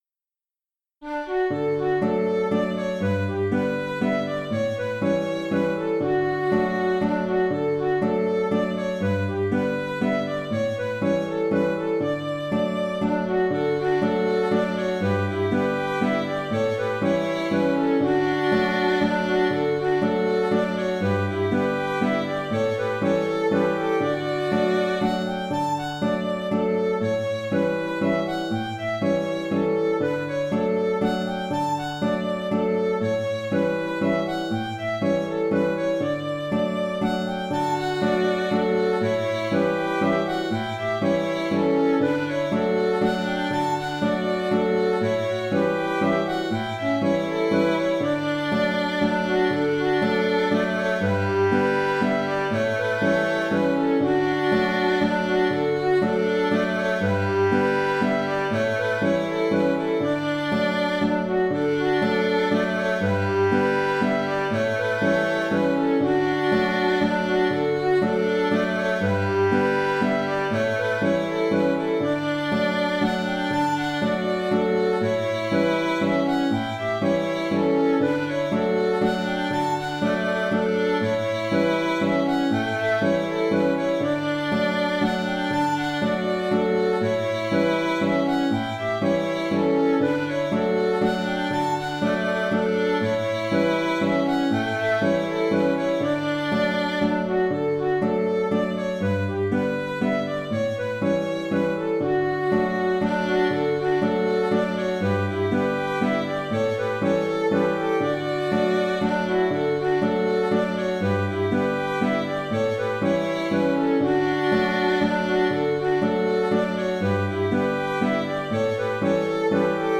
Cette mazurka traditionnelle est en ré, c’est-à-dire qu’elle nécessite, pour celles et ceux qui veulent la jouer à l’accordéon diatonique, d’avoir un trois rangs.
J’ai composé deux contrechants, à choisir ou à alterner. Ils s’enchaînent sur le fichier audio.
Mazurka